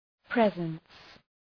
Προφορά
{‘prezəns}